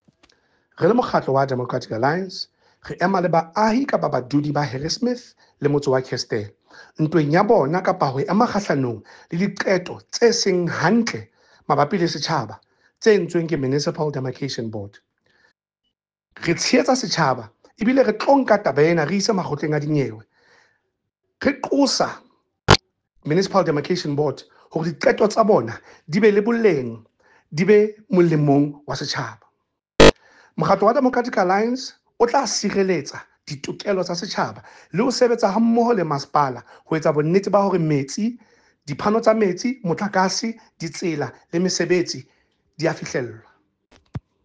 Sesotho soundbite by Cllr Eric Motloung